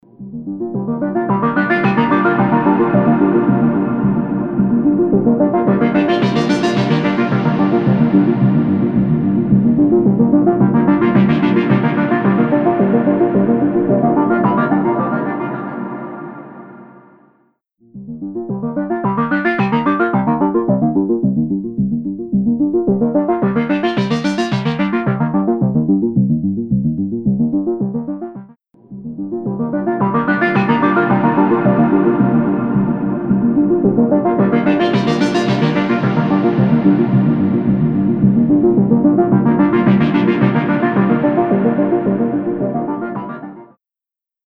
Massive Otherworldly Reverb
Blackhole | Synth Arp | Preset: Liquid Dream
Blackhole-Eventide-Synth-Arp-LiquidDream.mp3